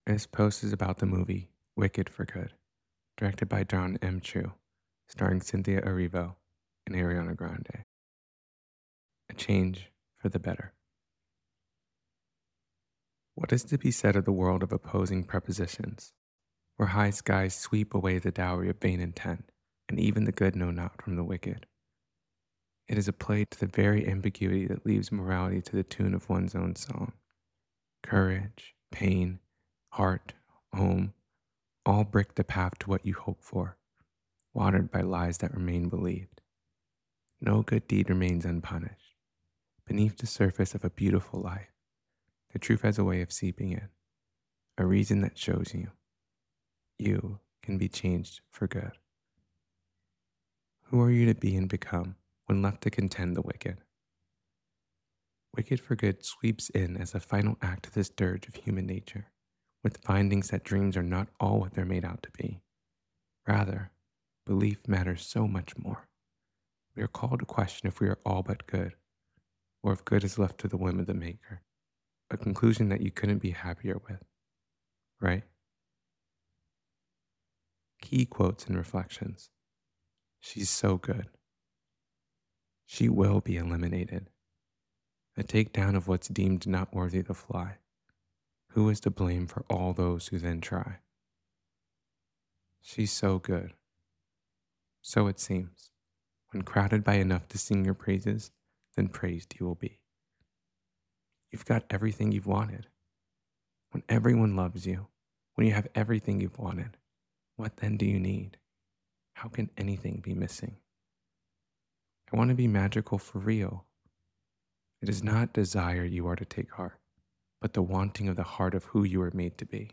wicked-for-good-to-know-a-story-reading.mp3